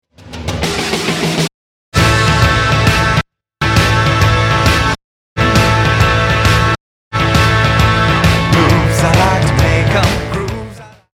The classic example of another Mannheim Rocket is in Mozart’s g minor symphony: